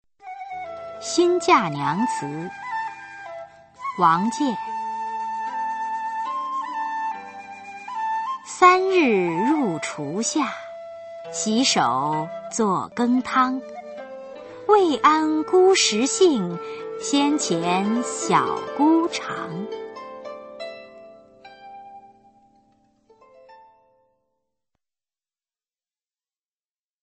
[隋唐诗词诵读]王建-新嫁娘词 配乐诗朗诵